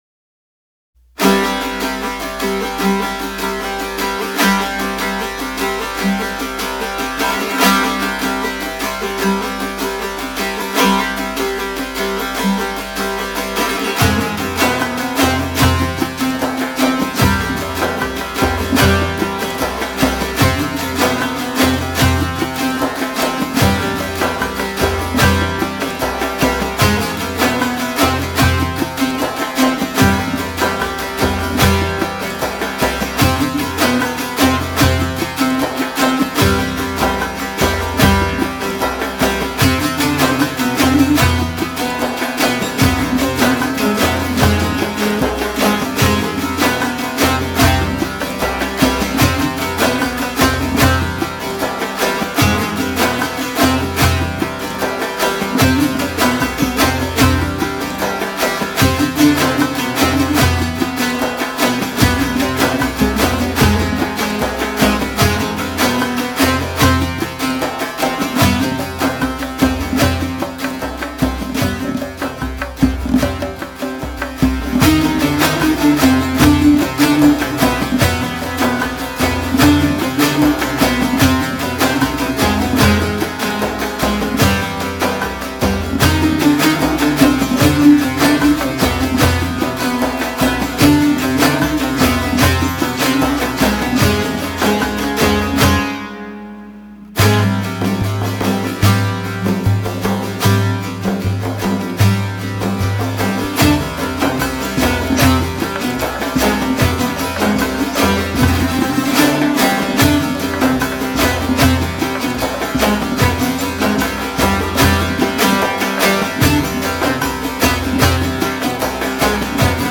اهنگ کردی